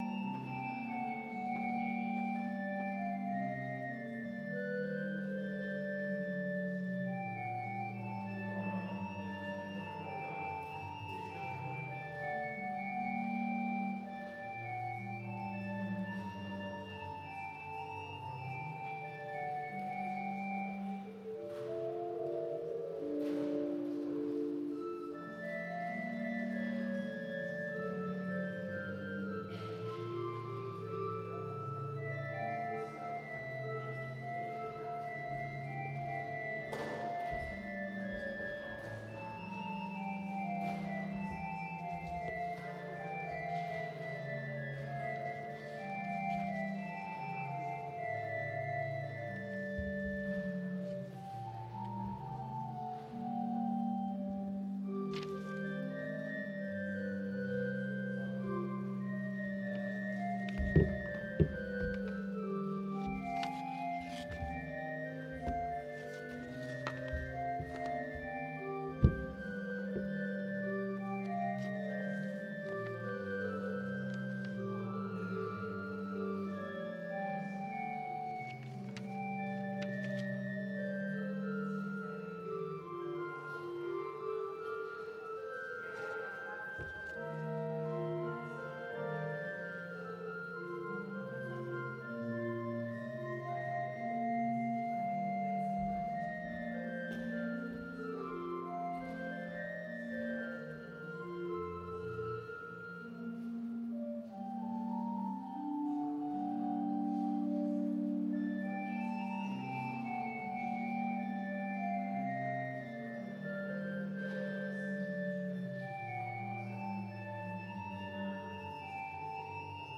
Christmas Eve Service on the 24th of December 2022
Full Service Audio